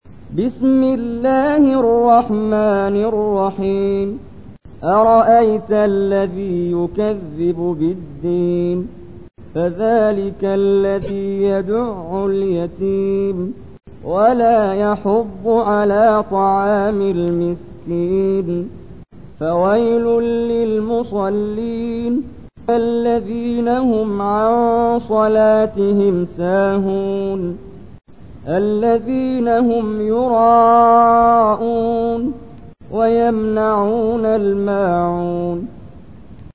تحميل : 107. سورة الماعون / القارئ محمد جبريل / القرآن الكريم / موقع يا حسين